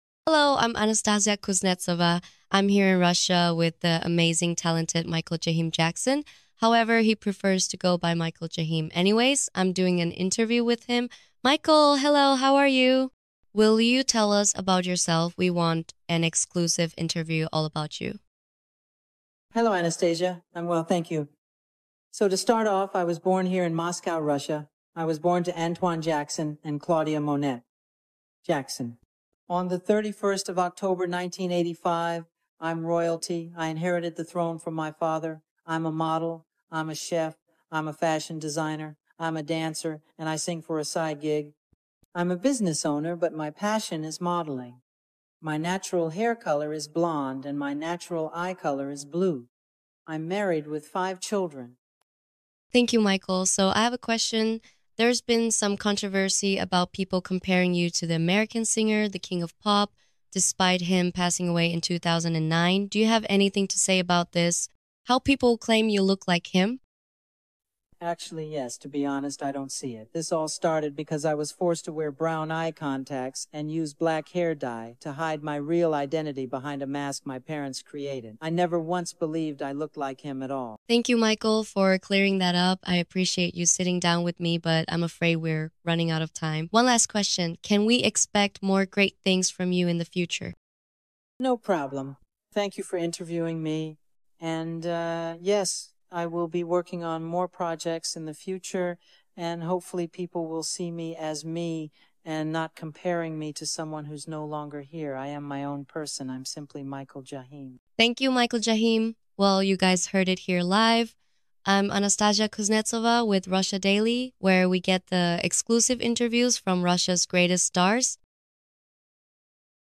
My interview inside my palace sound effects free download